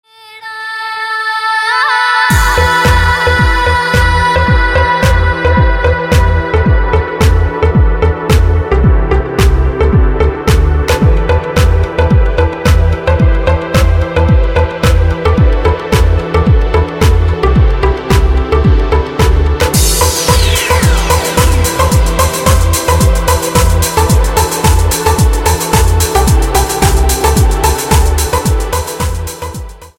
громкие
красивые
спокойные
Эмоции: радостные